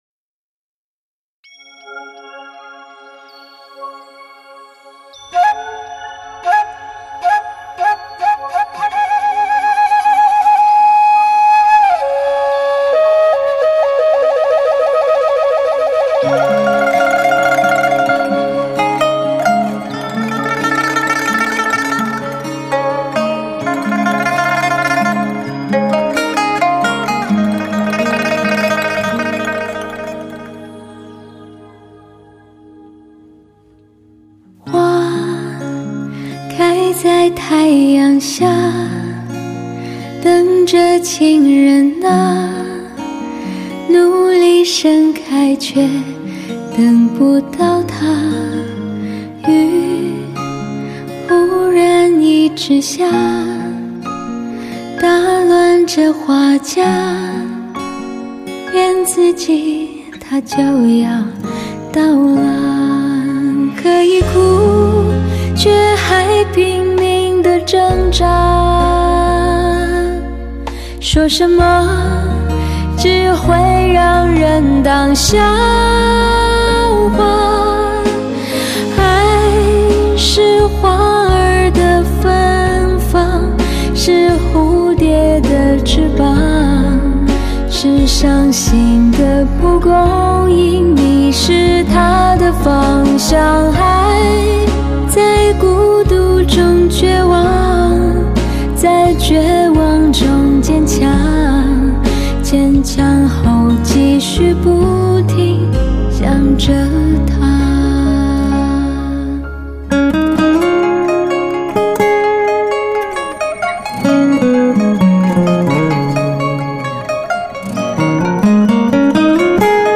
车载音乐－高临声环绕音乐